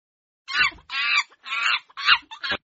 Screaming Sound Of A Monkey Téléchargement d'Effet Sonore
Screaming Sound Of A Monkey Bouton sonore